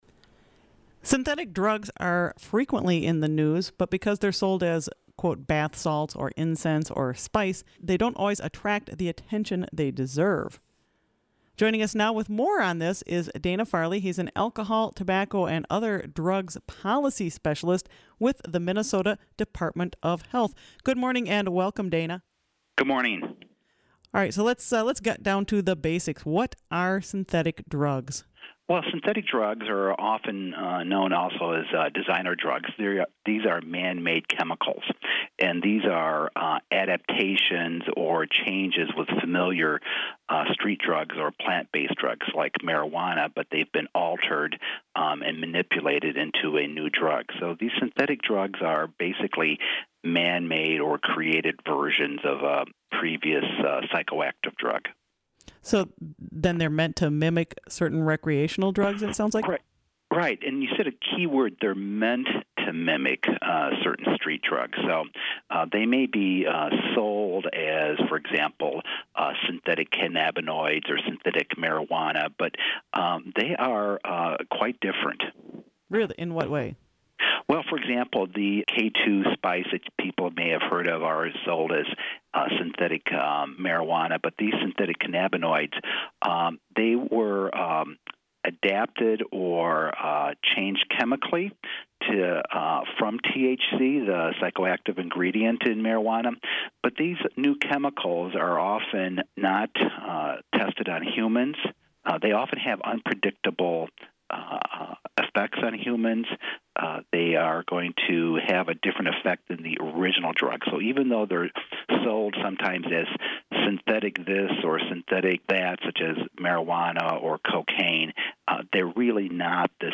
To learn about synthetic drugs - what they are, who uses them and what the dangers are - we contacted the Minnesota Department of Health.  WTIP volunteer